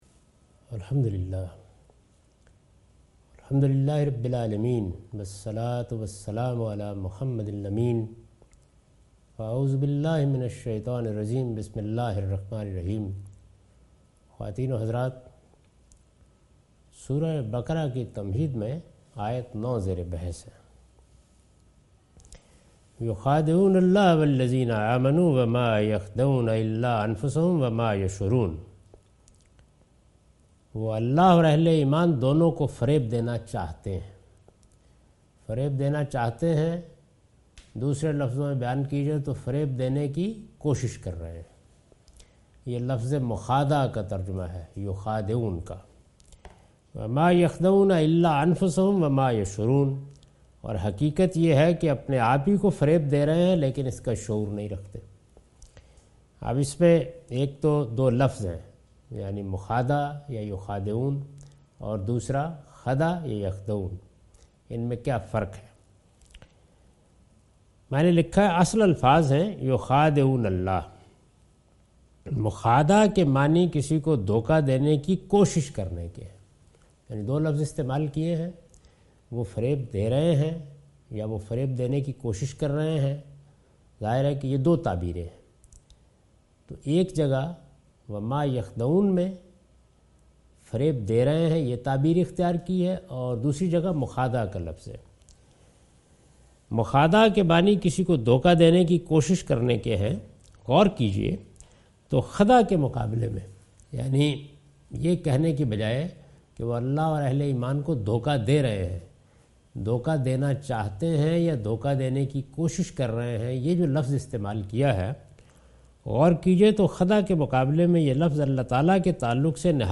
Surah Al-Baqarah - A lecture of Tafseer-ul-Quran – Al-Bayan by Javed Ahmad Ghamidi. Commentary and explanation of verse 9,10,11,12,13,14,15 and 16 (Lecture recorded on 4th April 2013).